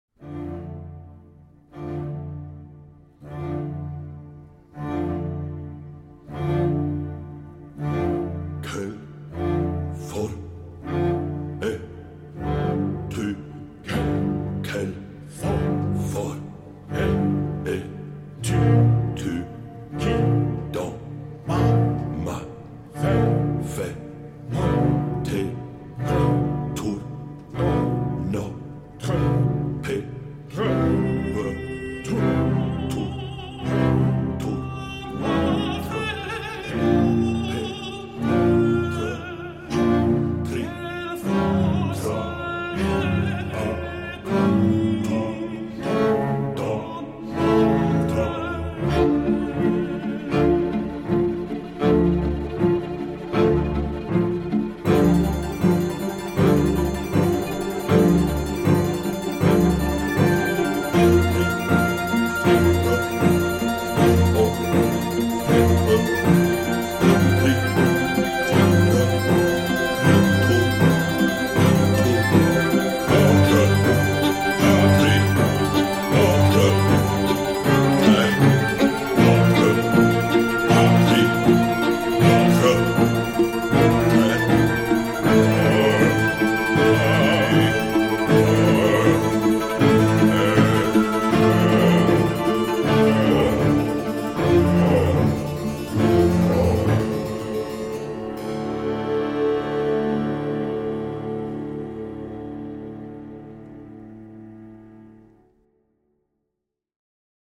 Rude, noir, effrayant parfois, sensible aussi.